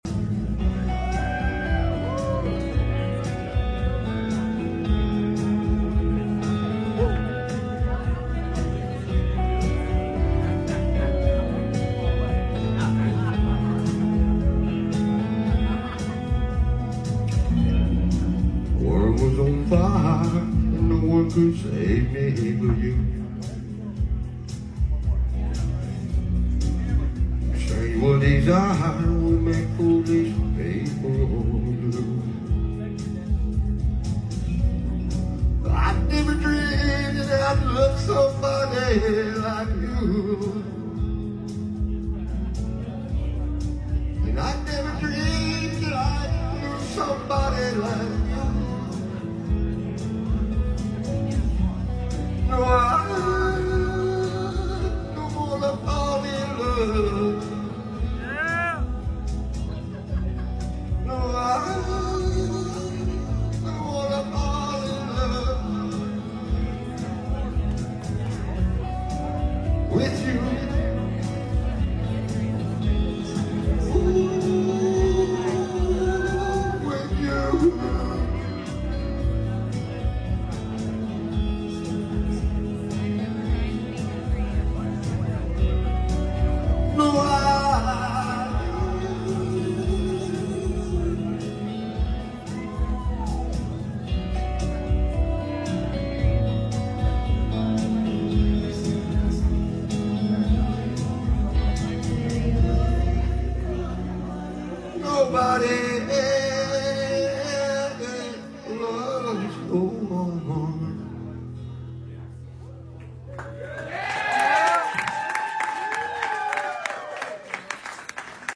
karaoke style!!